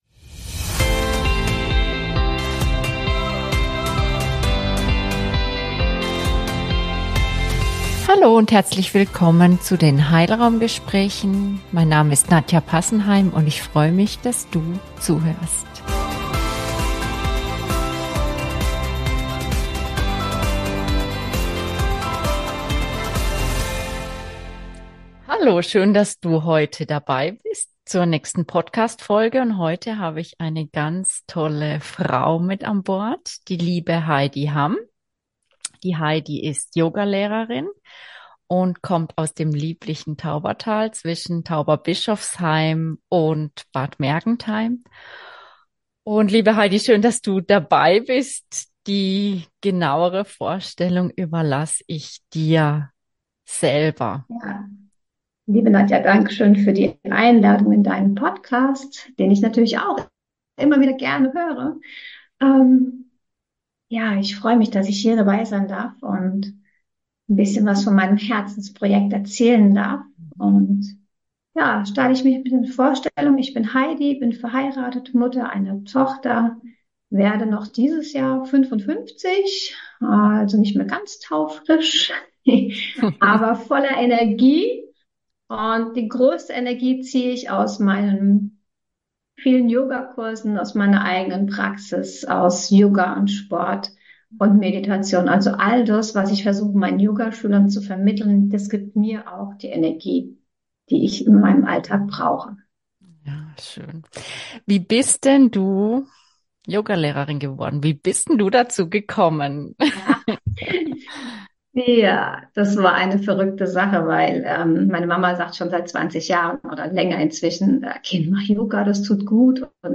Über Yoga und Krebs ~ HeilRaum – Gespräche